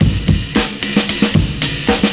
112CYMB04.wav